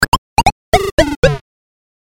描述：FX，快速循环，电子，提示音
标签： 120 bpm Electronic Loops Fx Loops 341.06 KB wav Key : Unknown
声道立体声